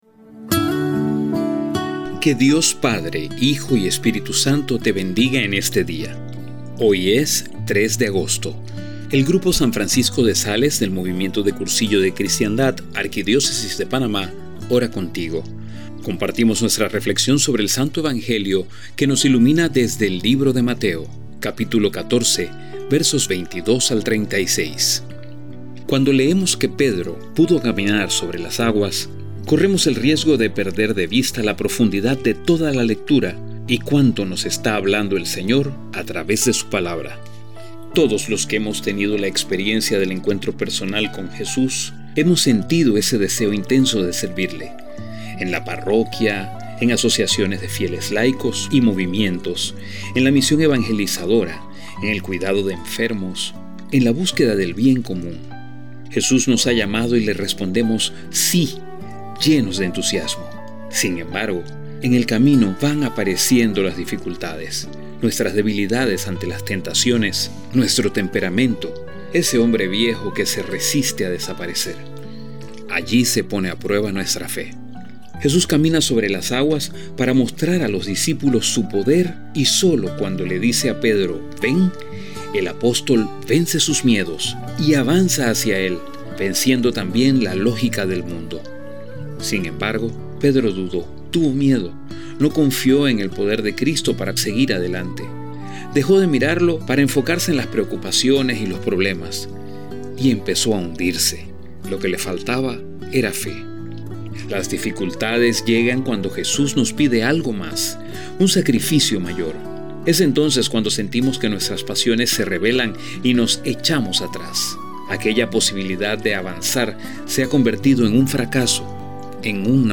A continuación la  audio-reflexión preparada por el grupo «San Francisco de Sales» del Movimiento de Cursillos de Cristiandad de la Arquidiócesis de Panamá, junto a una imagen para ayudarte en la contemplación.